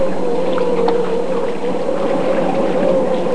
wind14.mp3